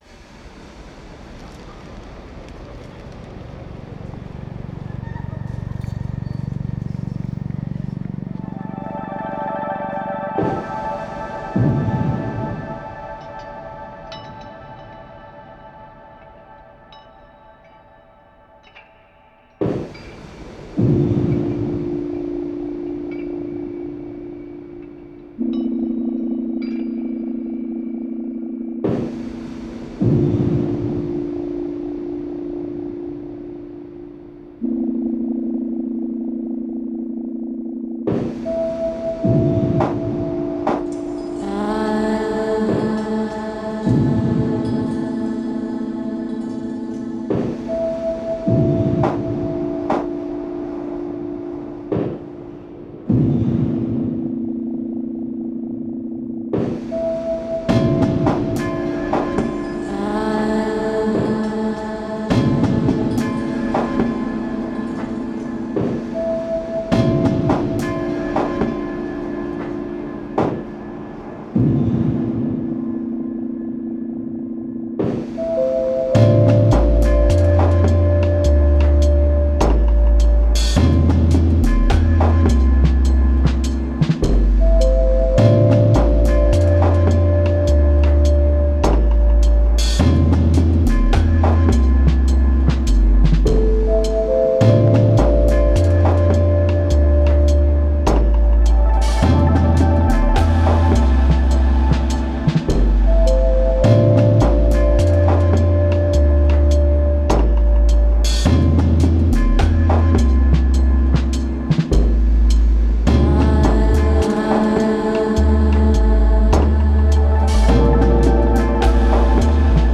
Genre: Downtempo, Ambient.